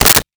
Switchboard Telephone Receiver Picked Up 02
Switchboard Telephone Receiver Picked Up 02.wav